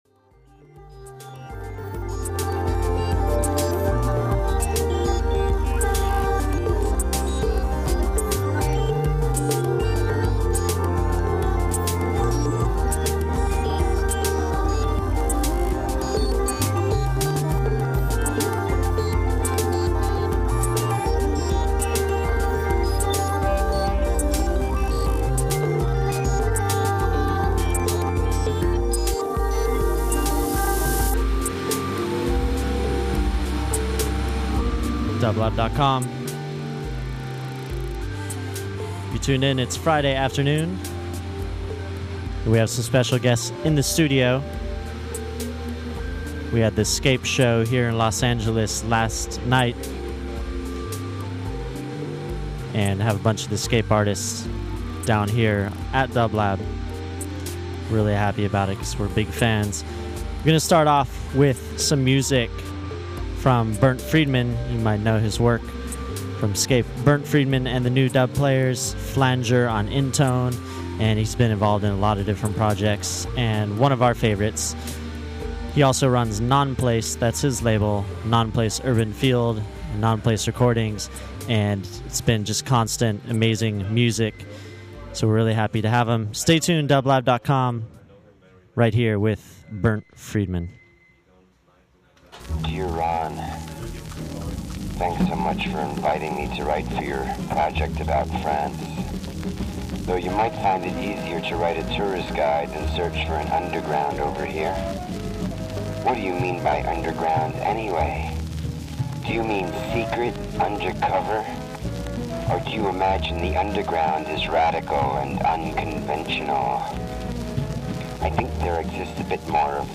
Electronic
dub-reggae cover
eccentric electronic pop gems of the ’60s